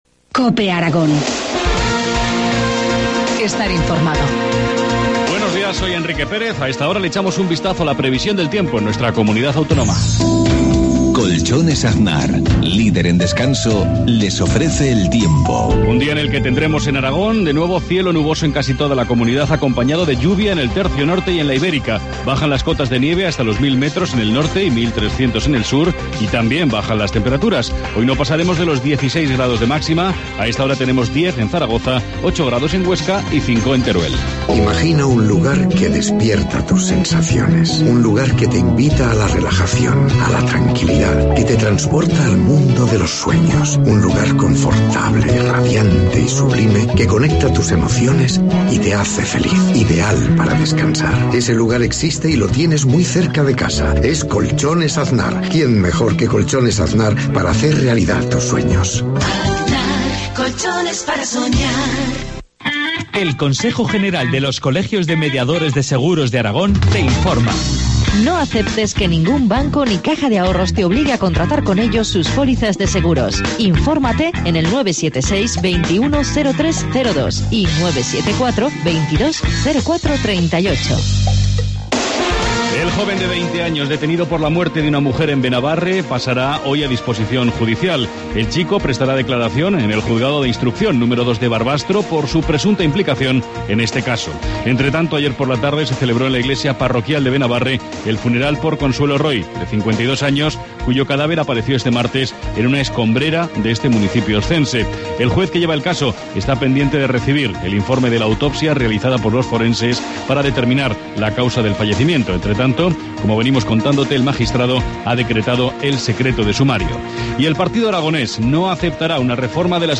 Informativo matinal, jueves 4 de abril, 7.53 horas